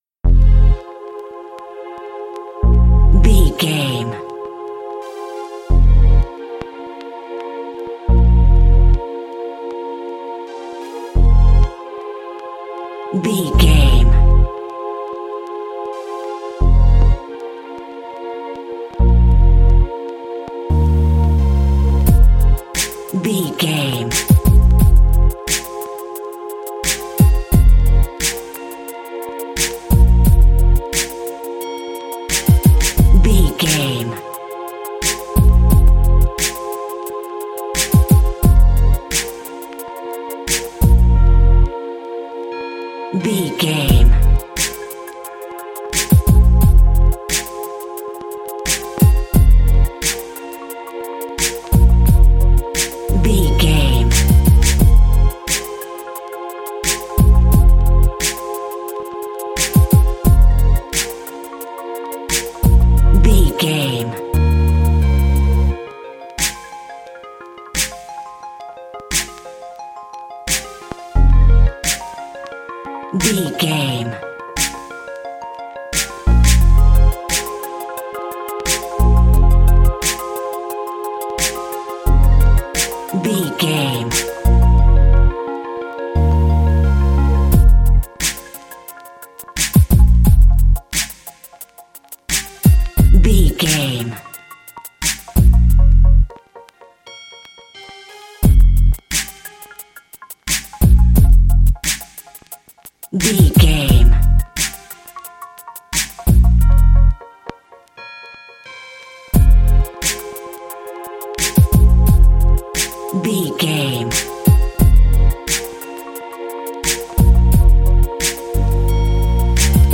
Aeolian/Minor
synthesiser
drum machine
hip hop
Funk
neo soul
acid jazz
energetic
bouncy
funky
lively